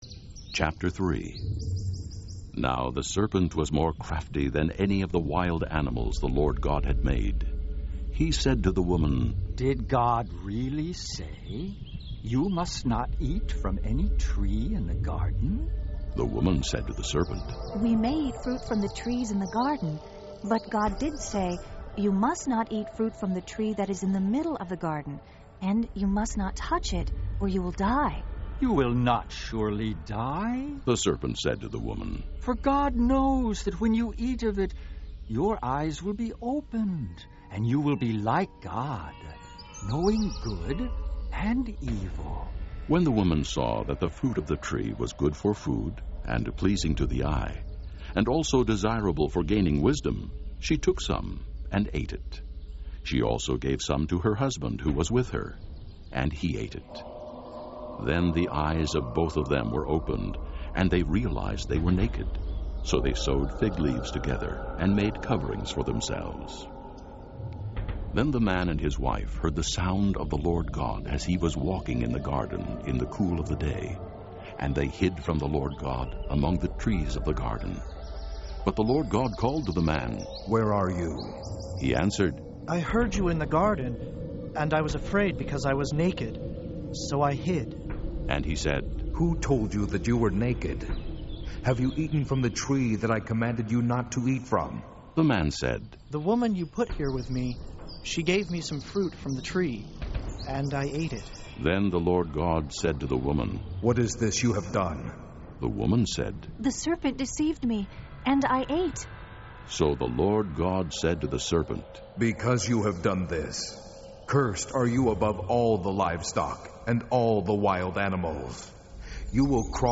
Субботние проповеди Download Проповедь о покаянии Other files in this entry Test-presentation.pptx Список_материалов.docx